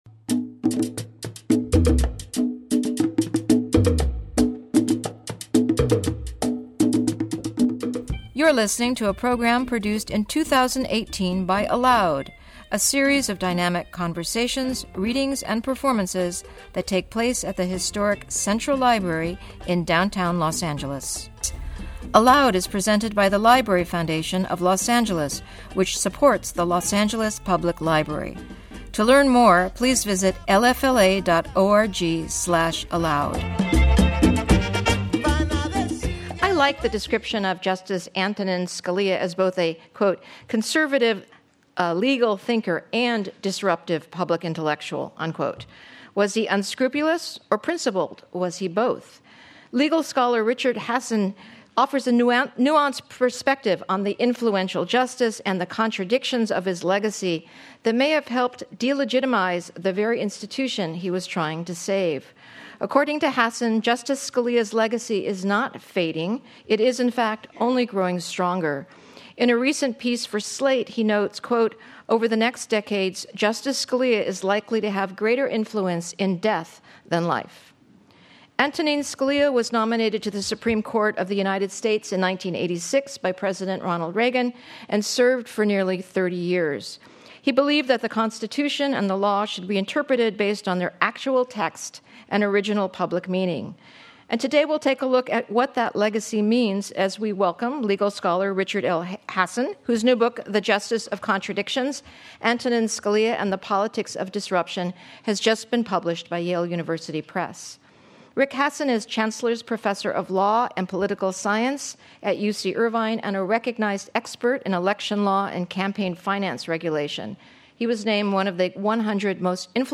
Rick Hasen In Conversation With Erwin Chemerinsky, Dean, Berkeley Law, University of California Wednesday, March 28, 2018 00:56:09 ALOUD Listen: play pause stop / aloudthe-justice-contradictions.mp3 Listen Download this episode